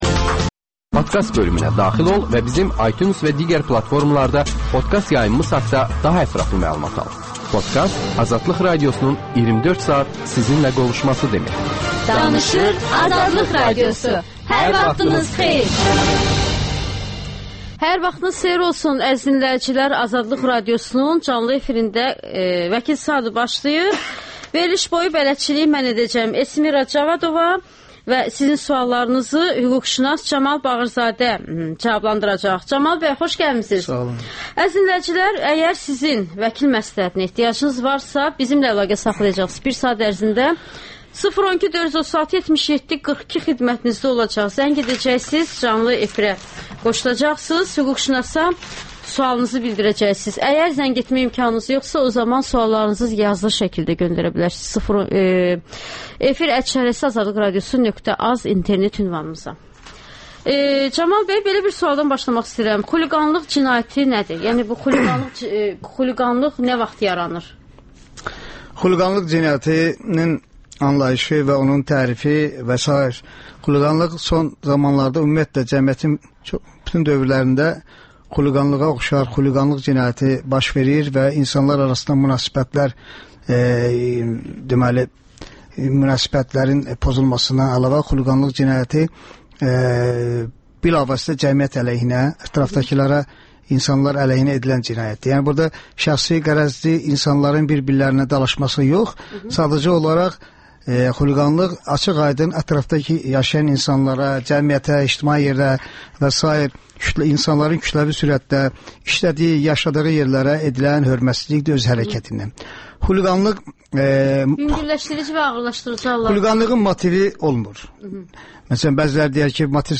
«Qaynar xətt» telefonunda dinləyicilərin suallarına hüquqşünaslar cavab verir.